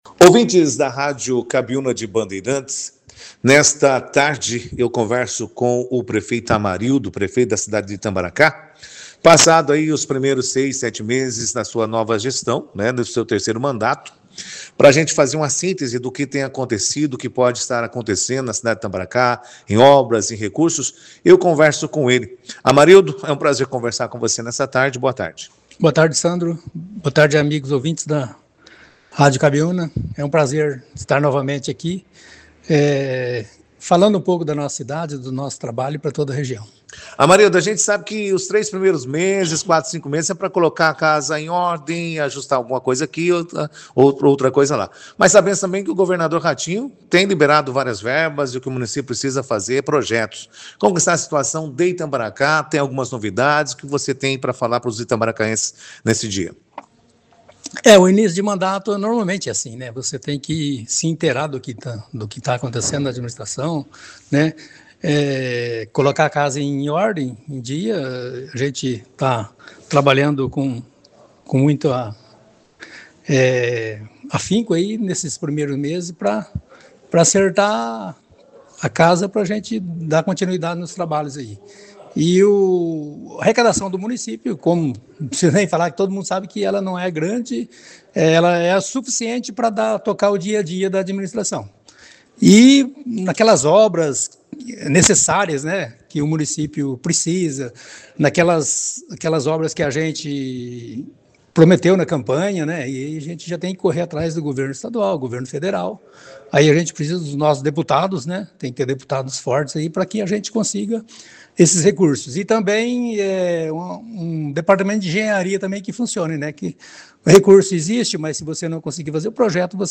O prefeito de Itambaracá, Amarildo Tostes, participou nesta quarta-feira, 23 de julho, da 2ª edição do jornal Operação Cidade, onde apresentou um balanço dos seis primeiros meses de sua gestão — o terceiro mandato à frente do município.